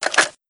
put_in_magazine.wav